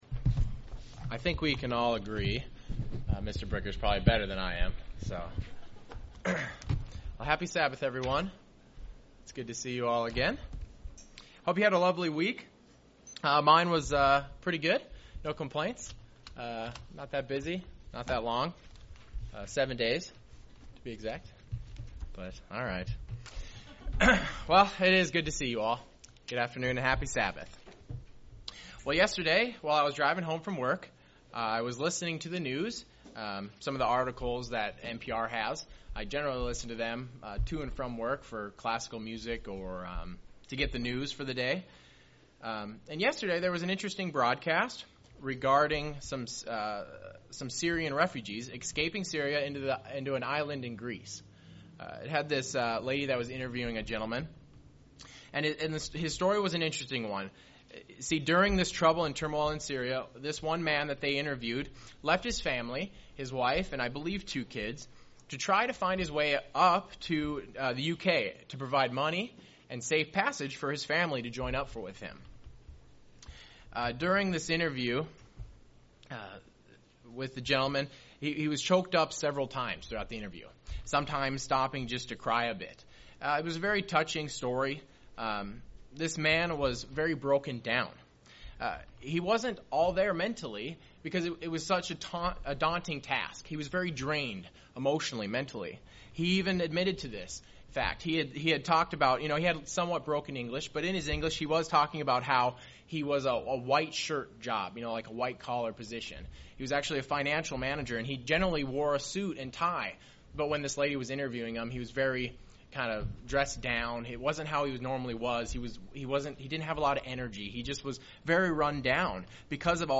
In this is sermon, the speaker looks into the topic of peace, true peace as defined in the bible.